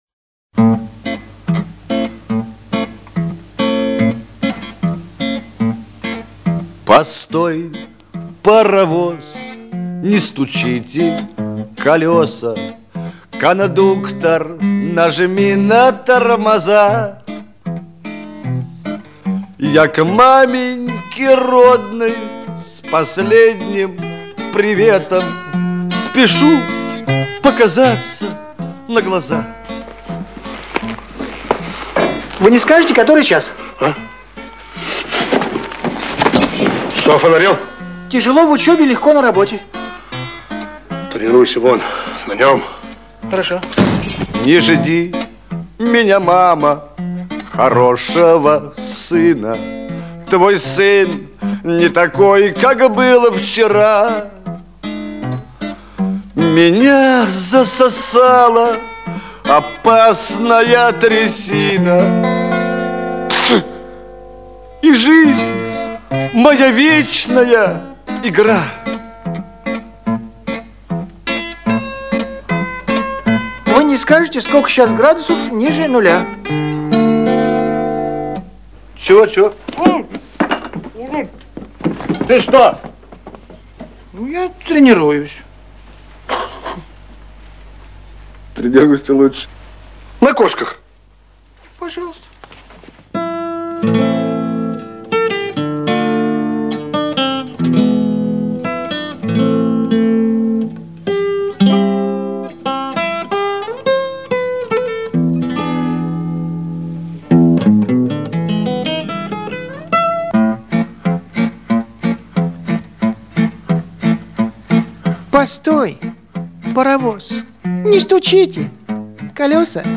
• Качество: 96, Stereo
гитара
OST
смешные
ретро